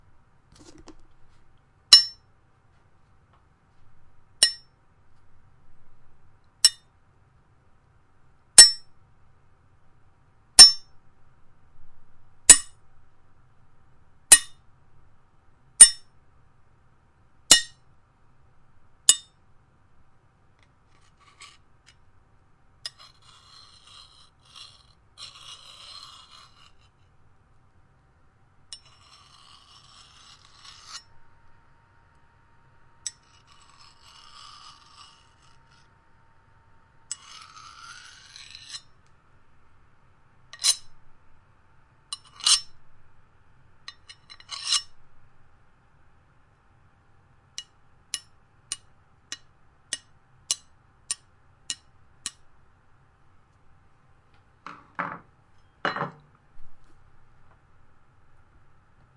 工具杂项 " 棘轮螺丝刀
描述：来自棘轮螺丝刀的各种声音。
Tag: 插座 螺丝刀 棘轮 工具 工具